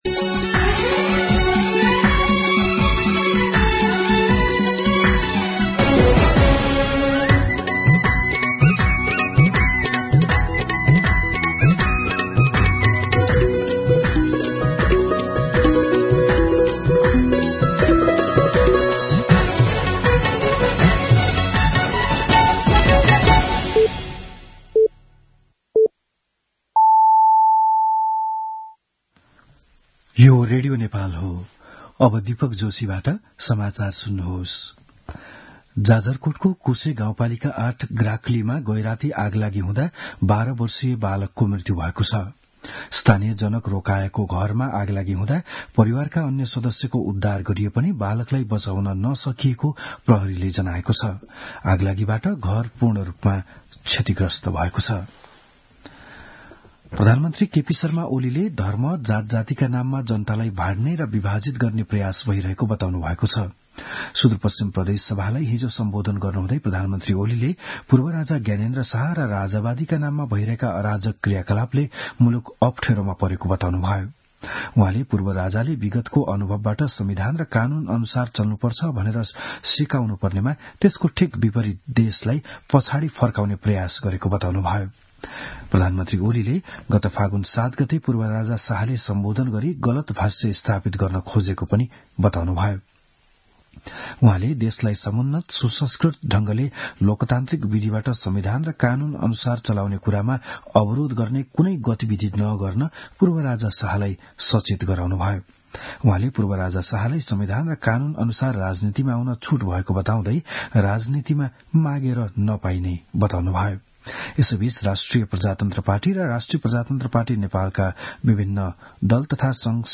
बिहान ११ बजेको नेपाली समाचार : २७ फागुन , २०८१
11am-News-26.mp3